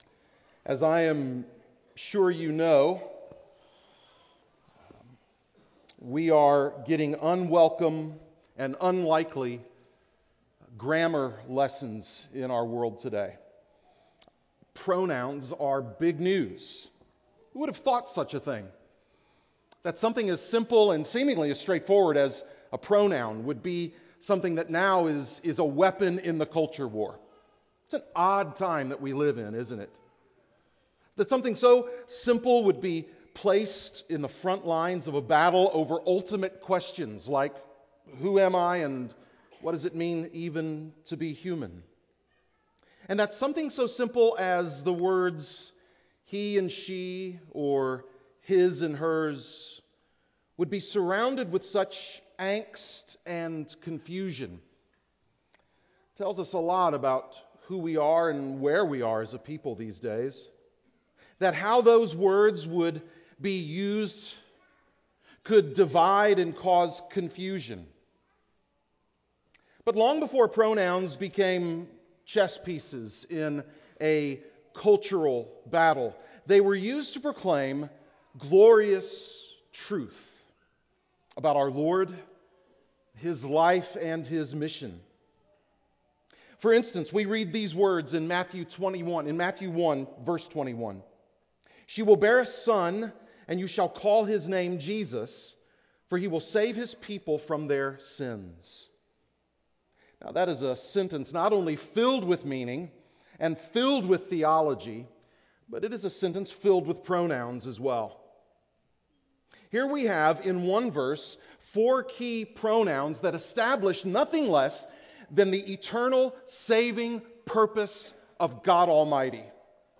Christmas Eve Sermon 2019